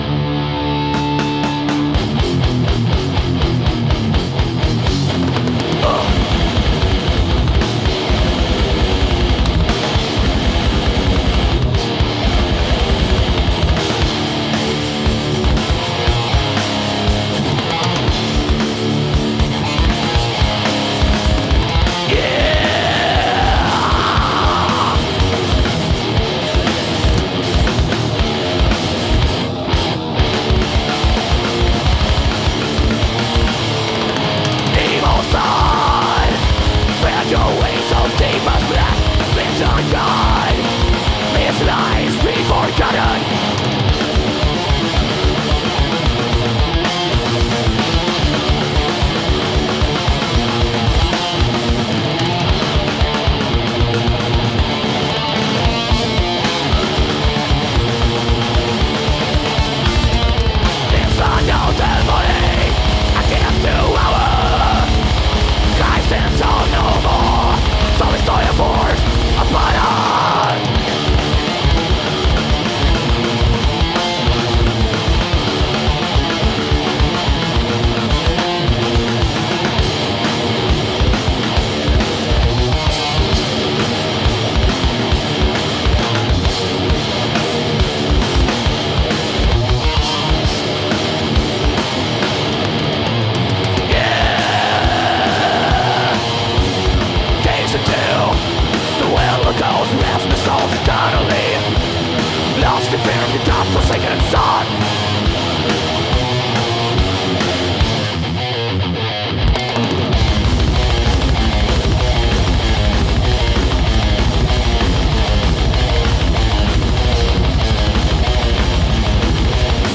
Live
Metal